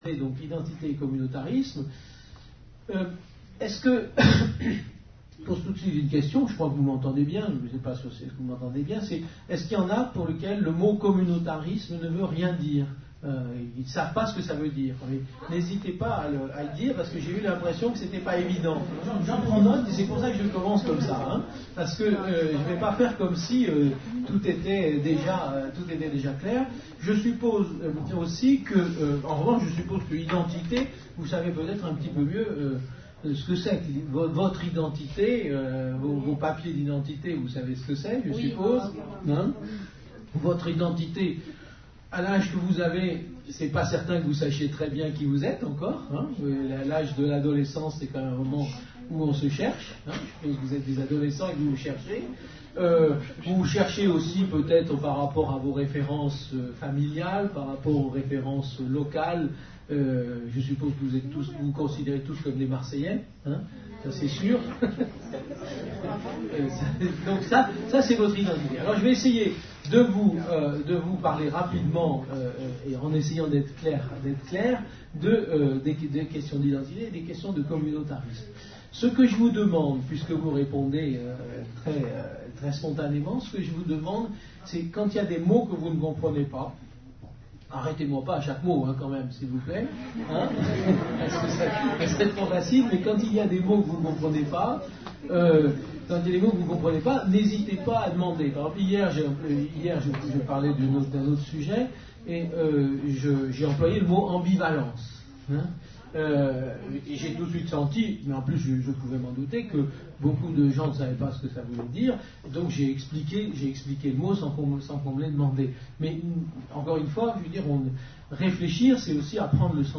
Une conférence de l'UTLS au lycée avec Yves Michaud (philosophe) Lycée de La Viste (13 Marseille) en partenariat avec la région PACA